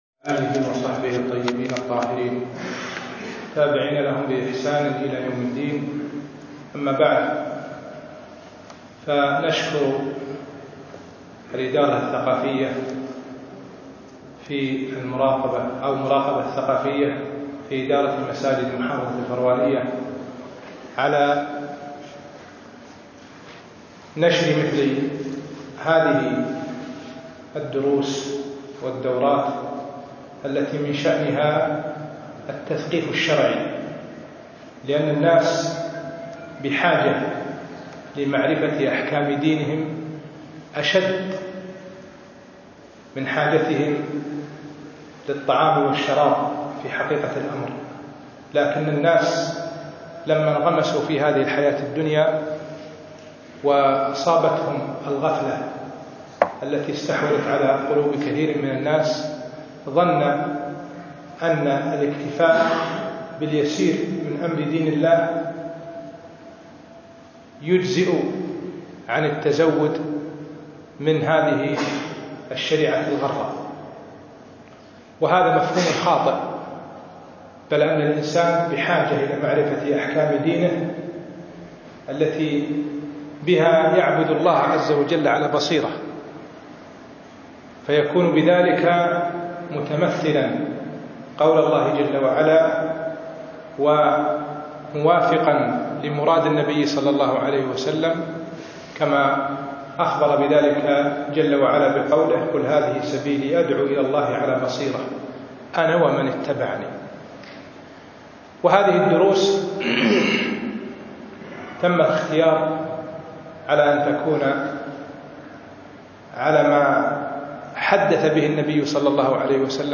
الأثنين 6 4 2015 بعد المغرب مسجد جلوي العربيد عبدالله المبارك
الدرس الأول